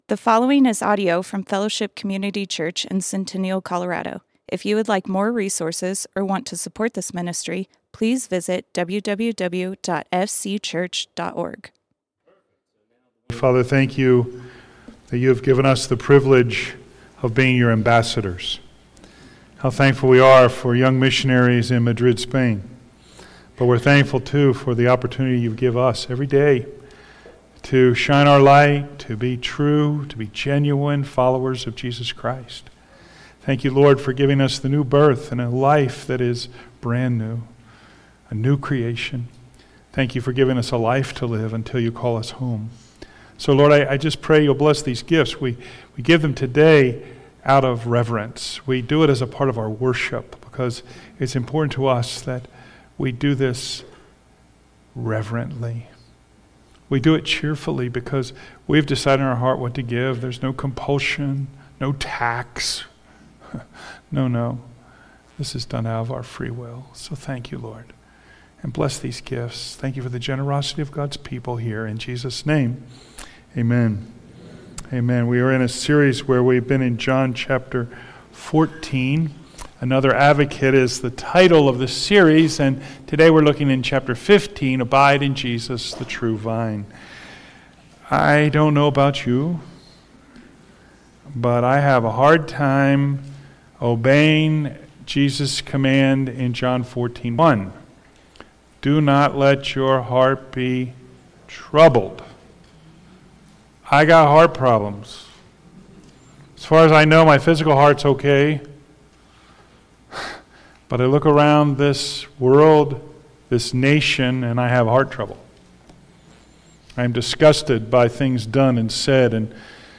Fellowship Community Church - Sermons Abide in Jesus Play Episode Pause Episode Mute/Unmute Episode Rewind 10 Seconds 1x Fast Forward 30 seconds 00:00 / 30:42 Subscribe Share RSS Feed Share Link Embed